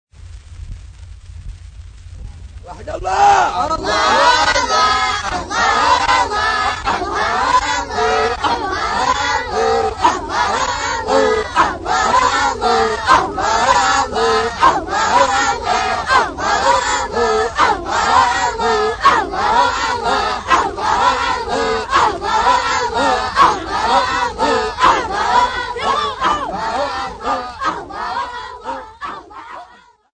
Arab/Swahili boys
Folk Music
Field recordings
Africa Kenya city not specified f-ke
sound recording-musical
Indigenous music